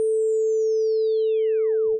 Lower Pitches.wav